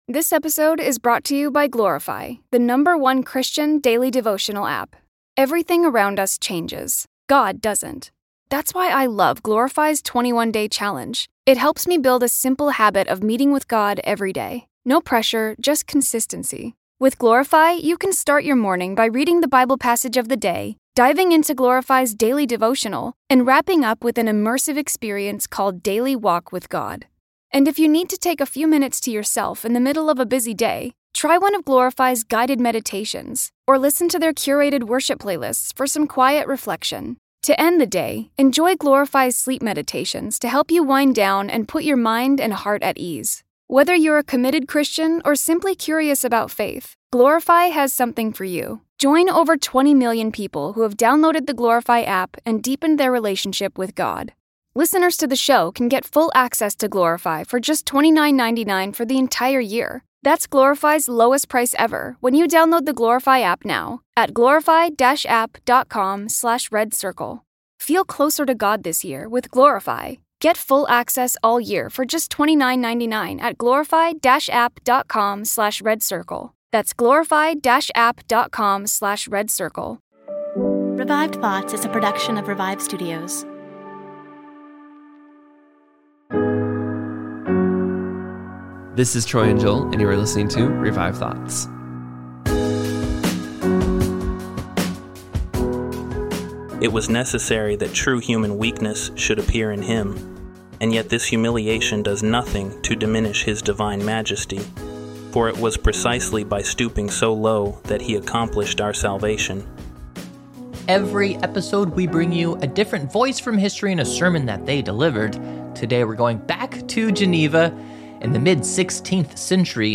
Every year we cover one of the famous 8 sermons by Calvin in his sermon passion sermon series. This year we cover sermon number 2.